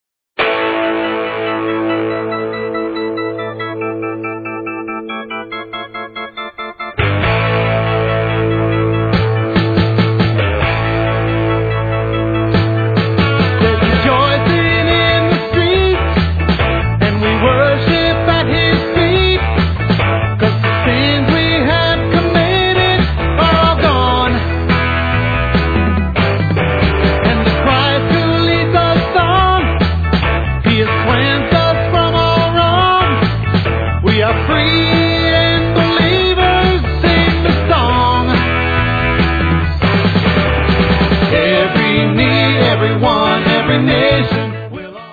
parody
You will love the upbeat music and fun Christian message.